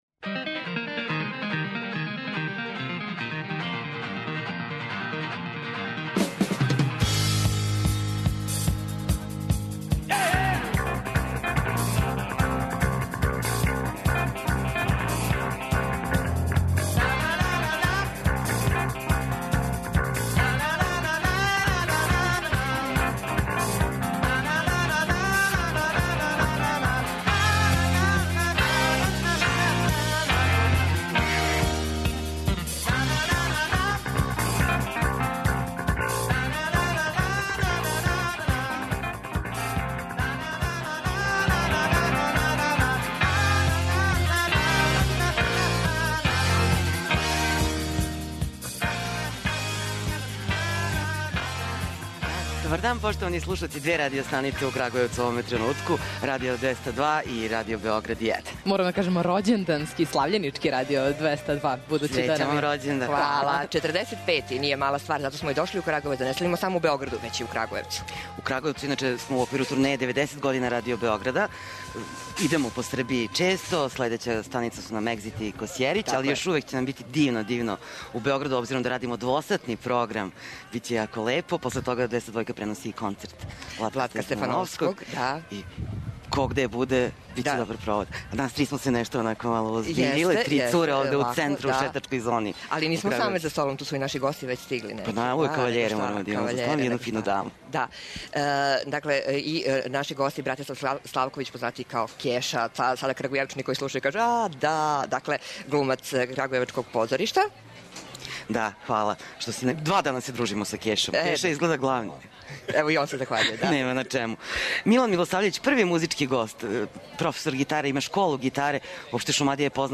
Специјално издање емисије, коју заједно са Радиом 202 емитујемо из Крагујевца, представиће, културни и уметнички живот тог града.